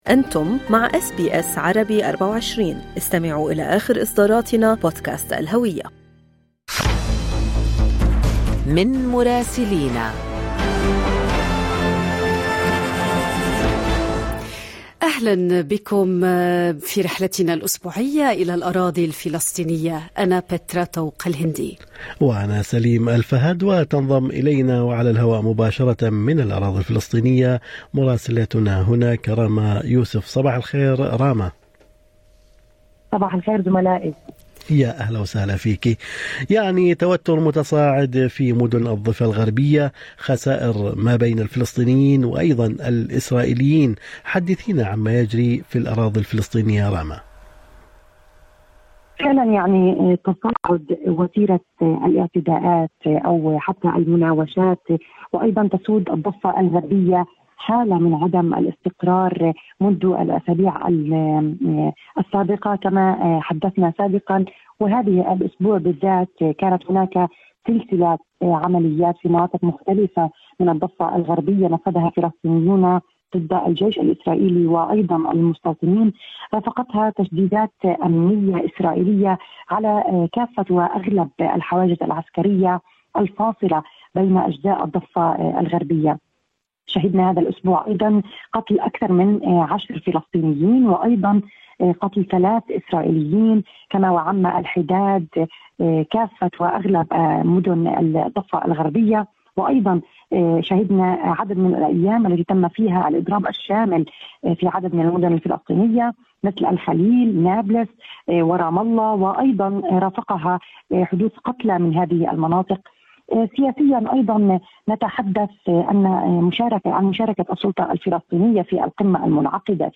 يمكنكم الاستماع إلى تقرير مراسلتنا في الأراضي الفلسطينية بالضغط على التسجيل الصوتي أعلاه.